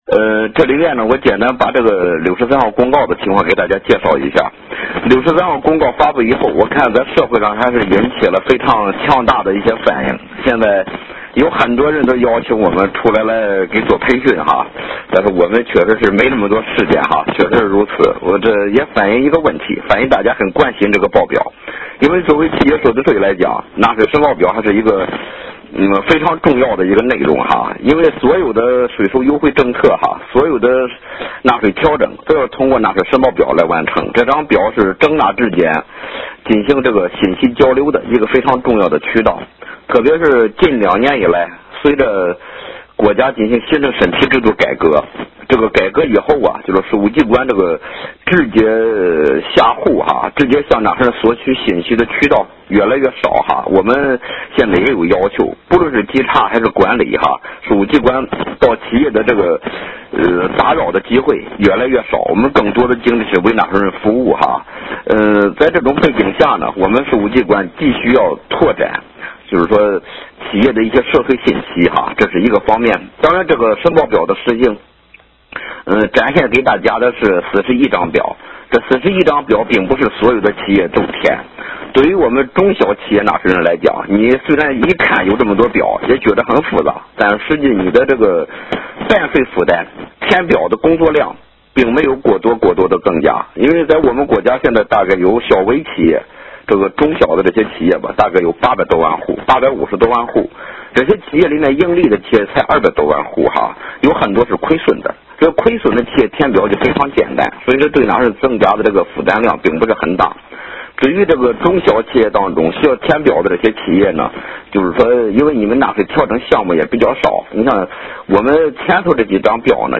电话会议
2)深度解读公告2014年第63号政策内容及要点 16:45 互动问答